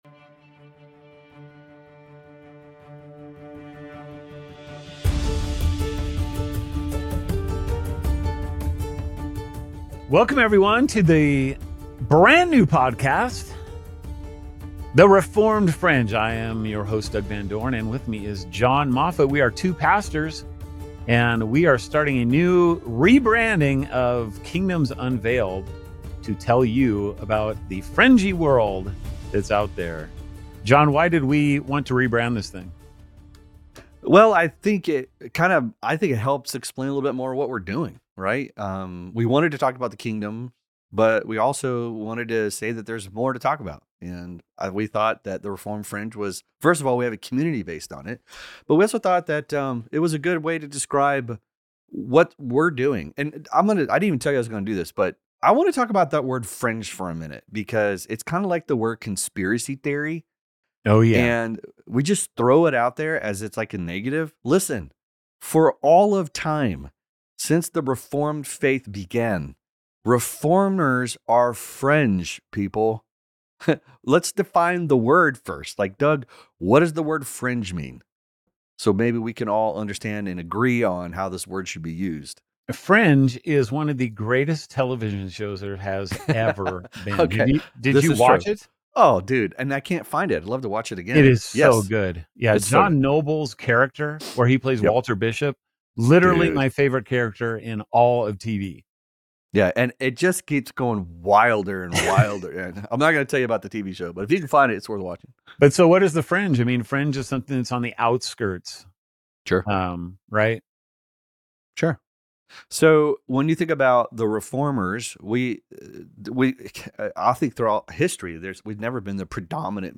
Reformed Fringe is a podcast where doctrine meets discovery. Weekly conversations at the intersection of reformed theology and contemporary exploration.